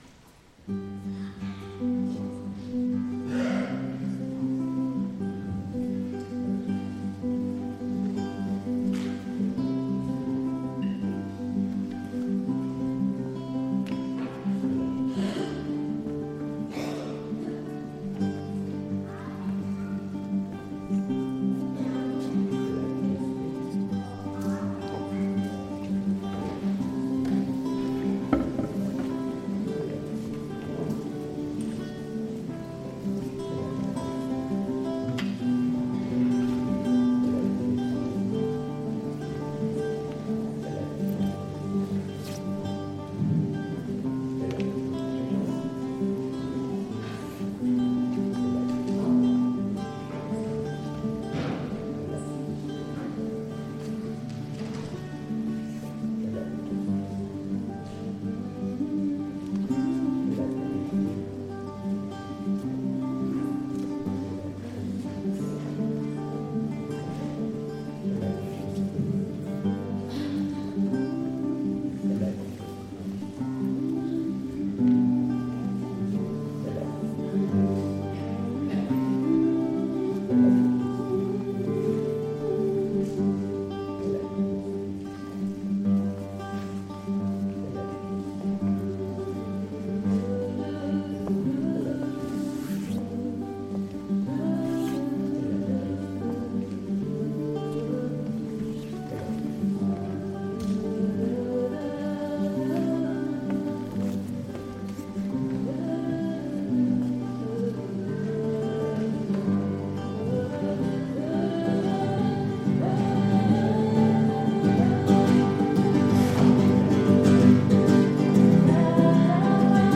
Die afrikanischen Lieder aus dem Gottesdienst
Instrumental_Gitarre_mp3